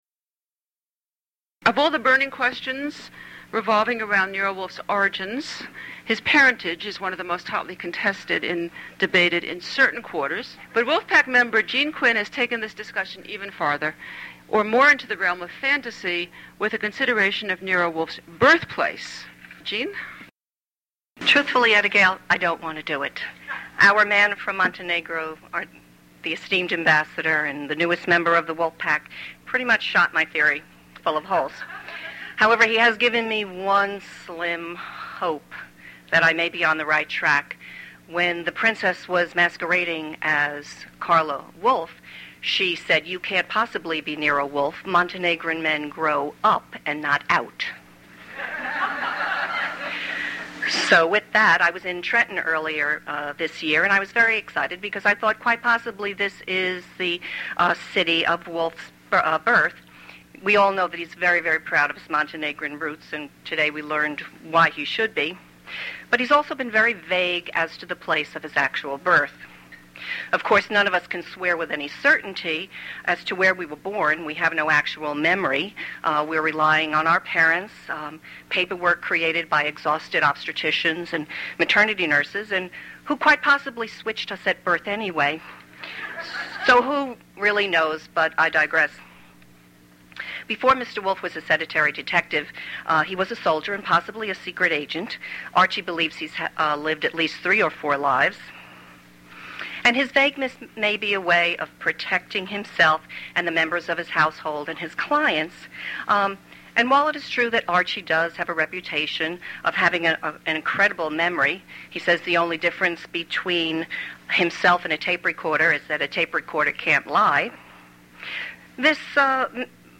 to the Wolfe Pack Black Orchid Weekend Assembly, December 1, 2007